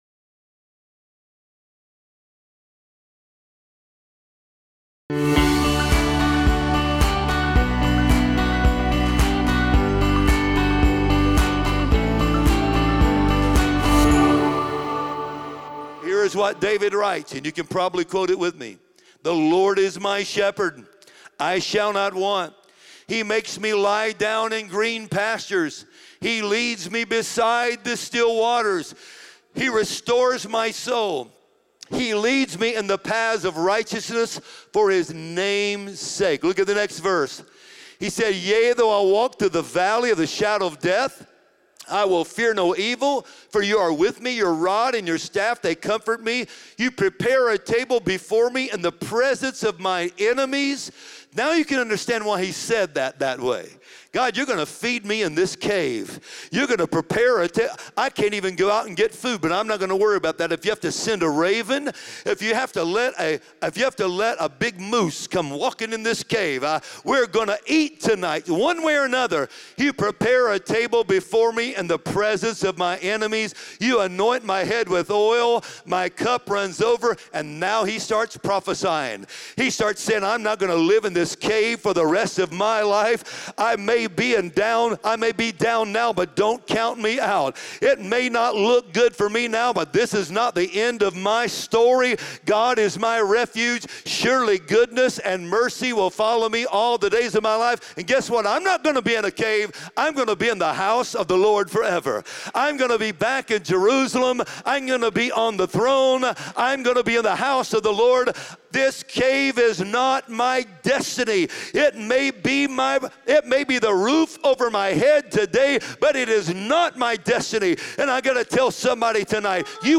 Discover how to find true rest and safety in God through Scripture and practical faith. This sermon will strengthen your trust and bring peace in uncertain times.